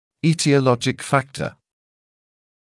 [ˌiːtɪə’lɔʤɪk ‘fæktə][ˌи:тиэ’лоджик ‘фэктэ]этиологический фактор